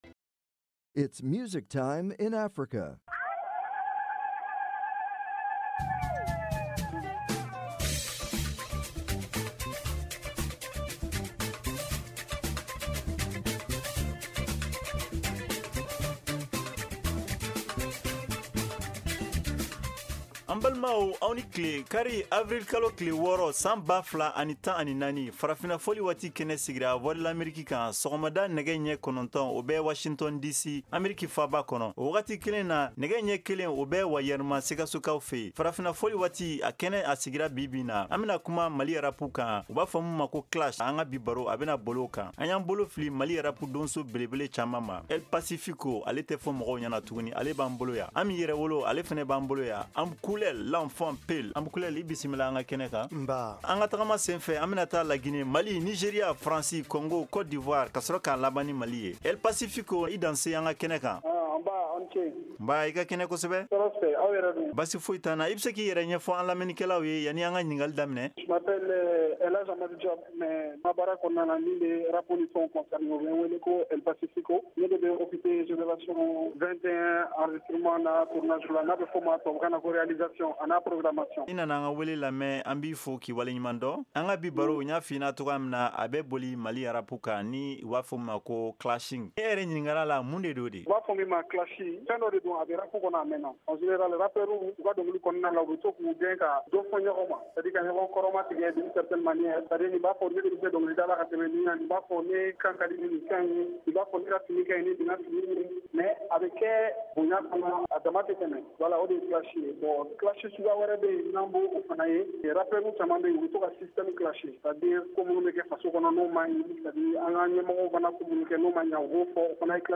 Farafina Fɔli Waati est une émission culturelle et musicale interactive en Bambara de la VOA.